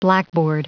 Prononciation du mot blackboard en anglais (fichier audio)
Prononciation du mot : blackboard